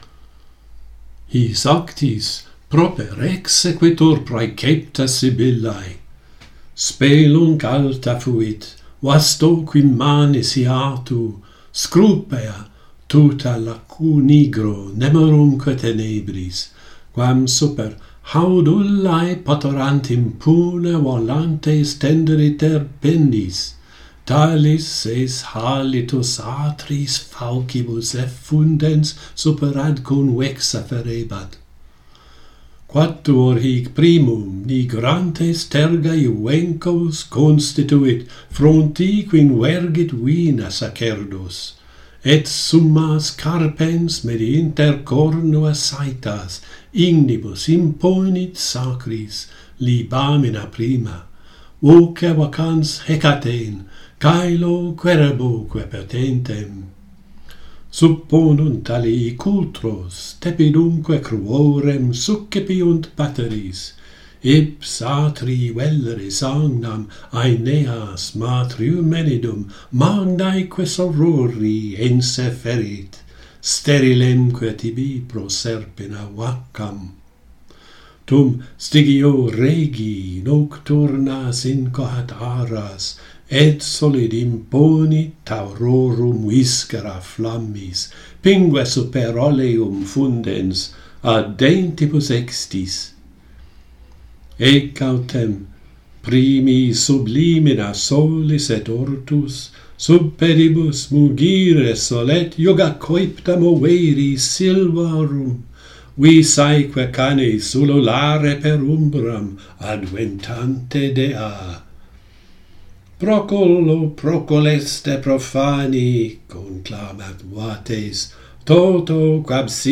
The journey to Hades begins - Pantheon Poets | Latin Poetry Recited and Translated